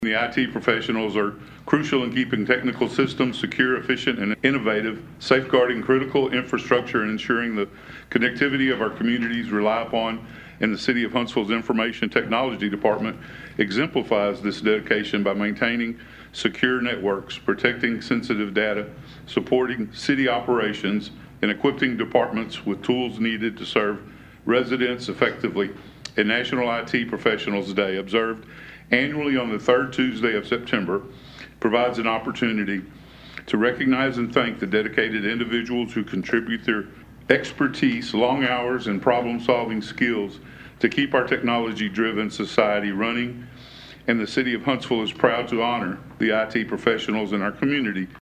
At the last Huntsville City Council session, Mayor Russell Humphrey honored the City of Huntsville’s IT crew proclaiming National IT Professionals Day.